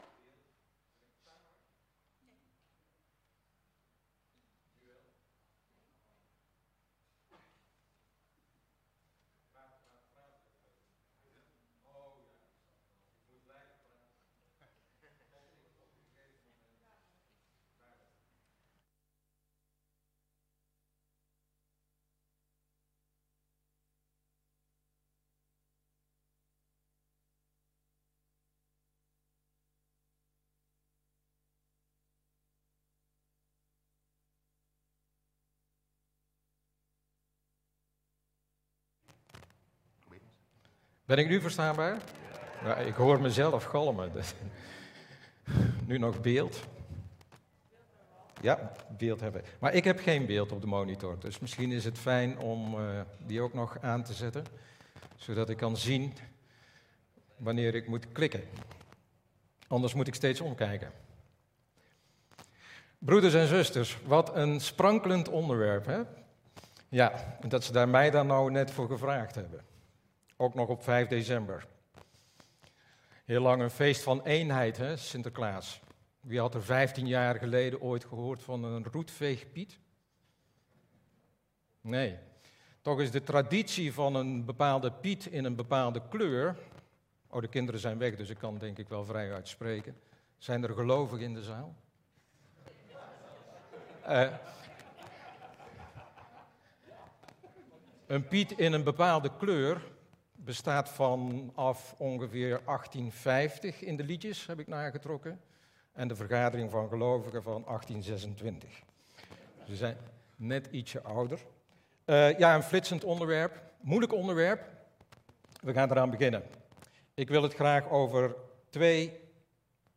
Toespraak 5 december: Verschillend denken...en toch één zijn?